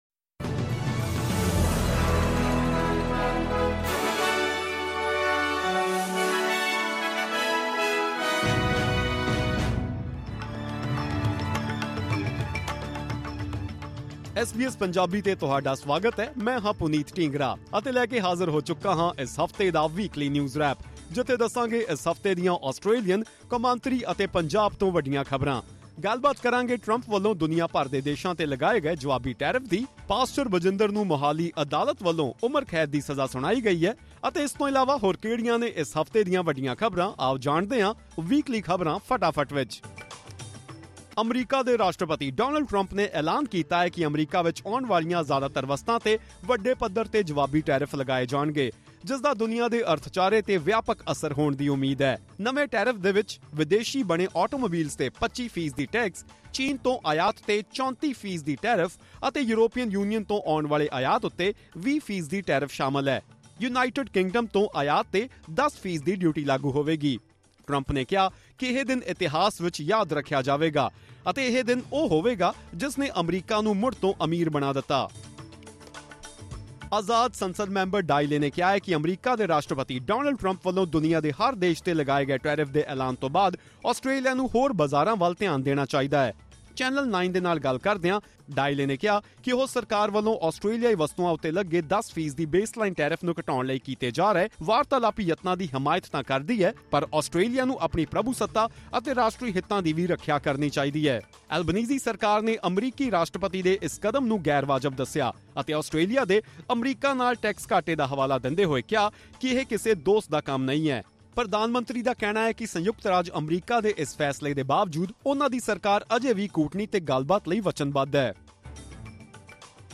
This week’s news wrap brings you the biggest stories from around the world, Australia, and India. Former U.S. President Donald Trump has imposed retaliatory tariffs on countries worldwide. In Australia, two individuals were arrested at the MCG during an AFL match with loaded firearms. Meanwhile, a Mohali court has sentenced Pastor Bajinder to life imprisonment.